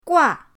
gua4.mp3